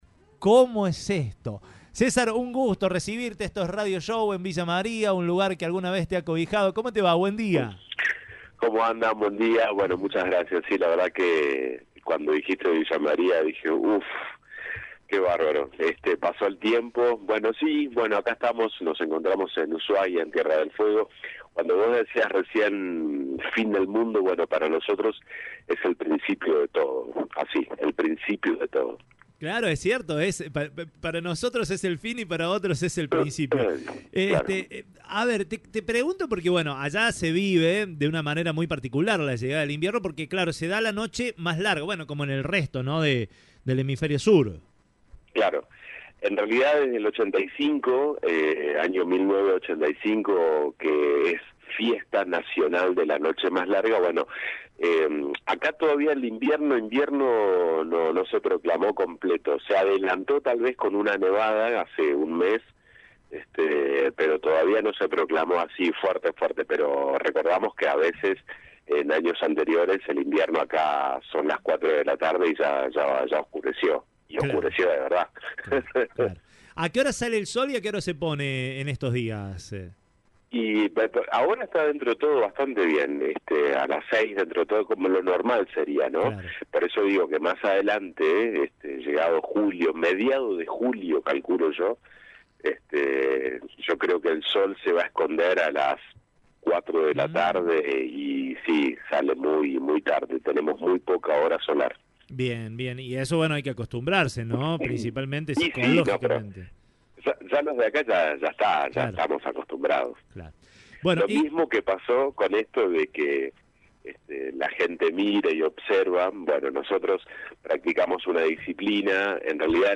En diálogo con Radio Show, contó cómo fue la actividad y como se preparan para nada en aguas frías o gélidas.